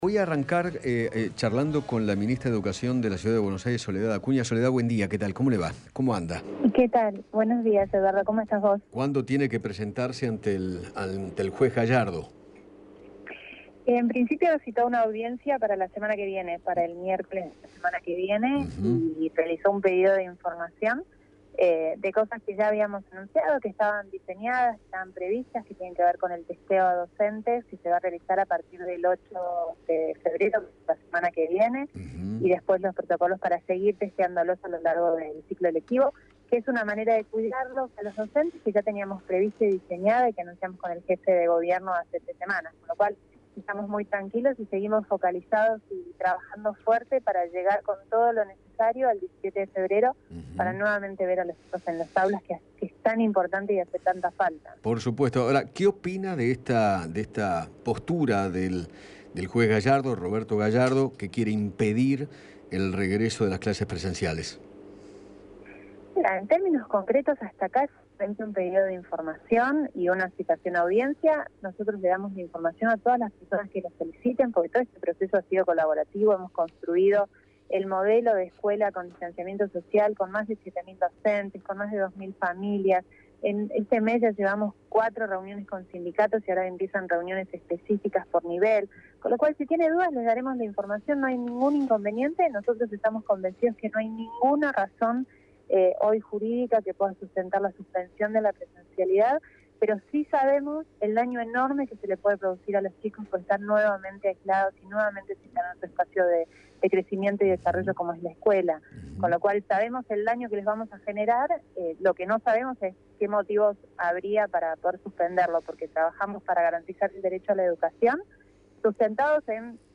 Soldedad Acuña, ministra de Educación de la Ciudad de Buenos Aires, dialogó con Eduardo Feinmann sobre la citación del juez Roberto Gallardo para que informe junto a Fernán Quiros cómo será el inicio de clases.